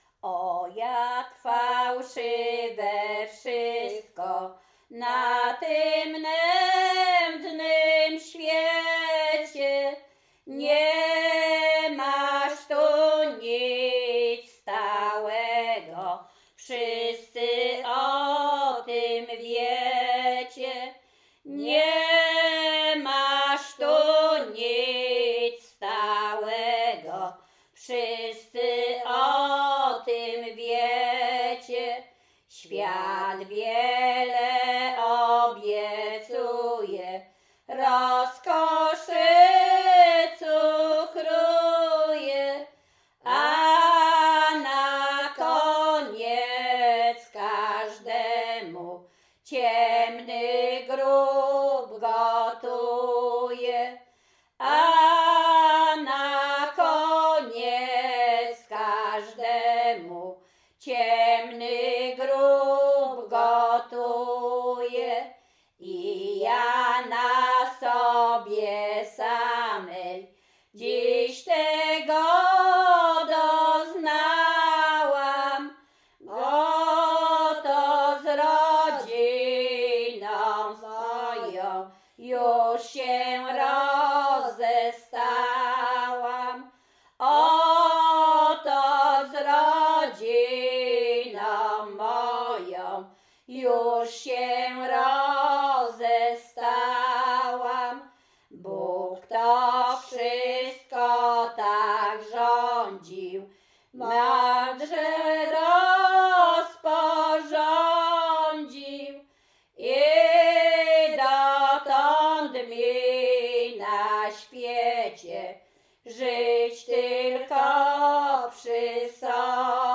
performer Śpiewaczki z Czerchowa
Spatial Coverage Łęczyckie
genre Pogrzebowa
Czerchów Tags pogrzebowe nabożne katolickie do grobu New Tags I agree with ter